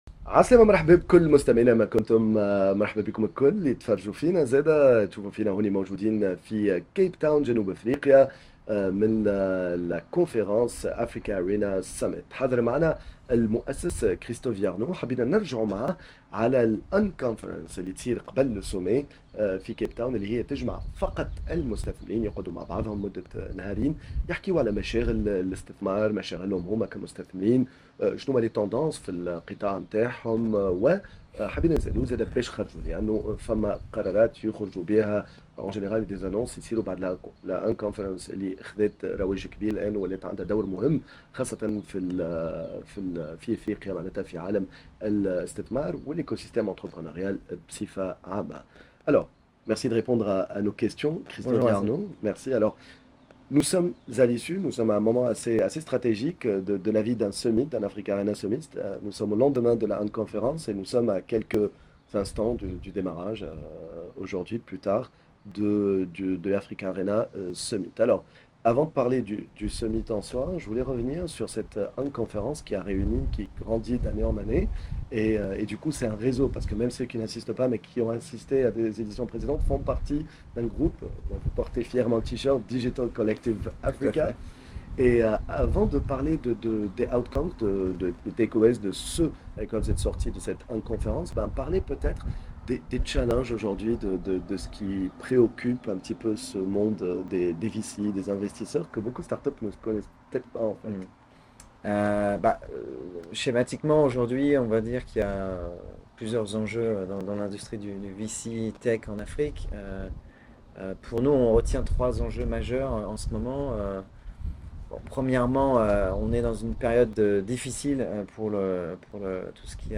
en direct de l'un des plus importants événements d'investisseurs en startups Africarena à Capetown